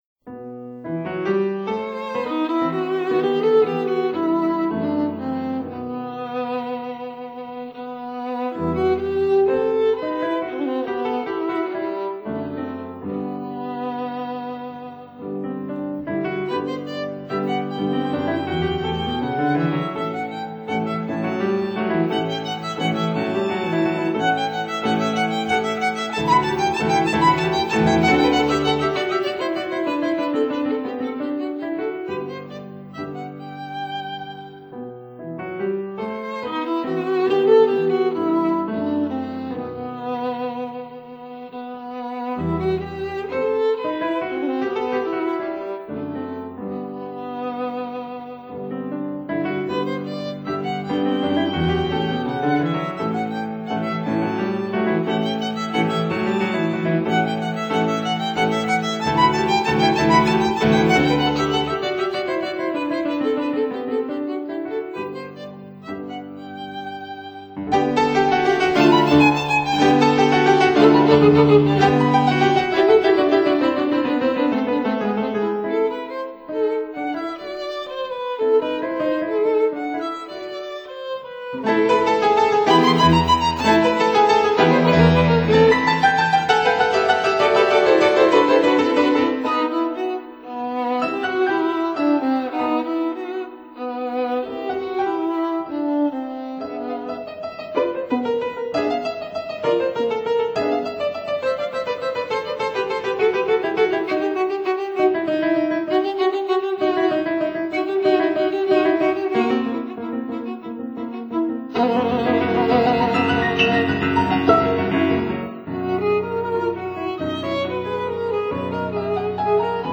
violin
piano